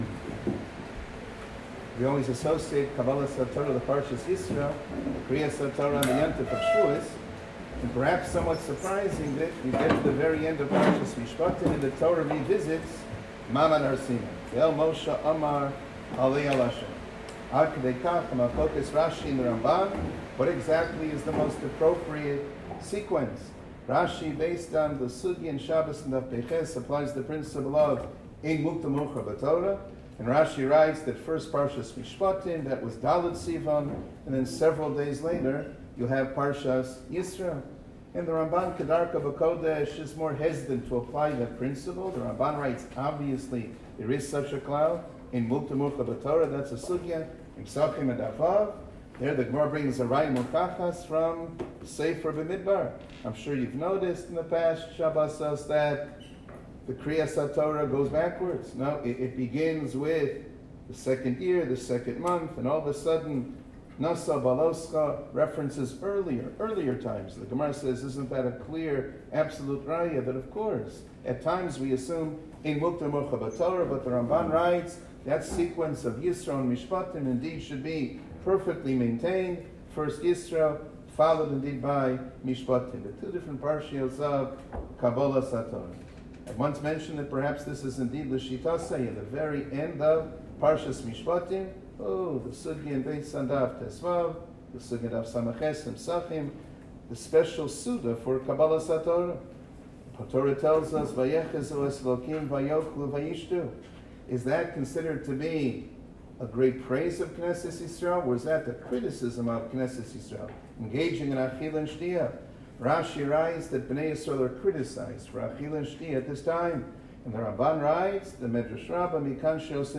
שיעור כללי - תלמוד תורה וברכותיה